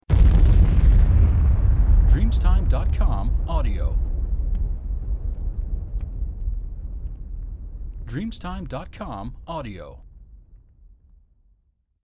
Explosion 007